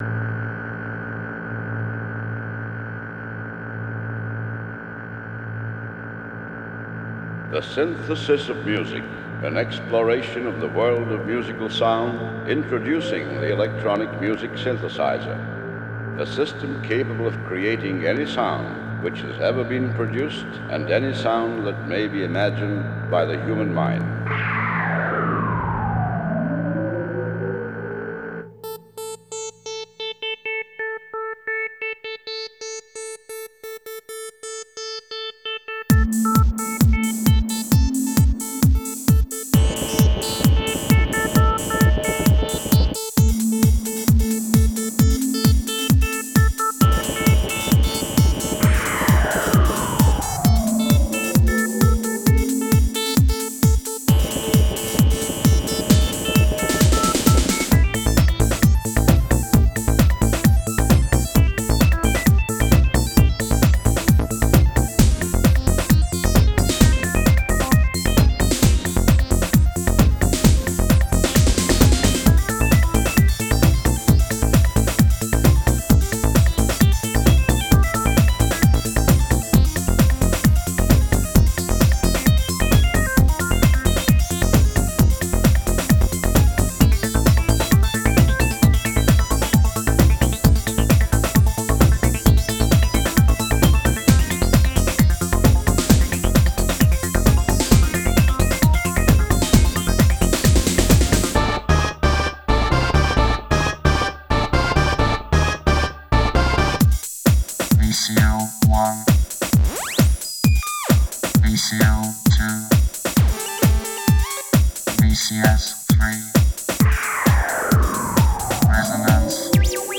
experimentellen Track
Synsound Studio
Vocoder Stimme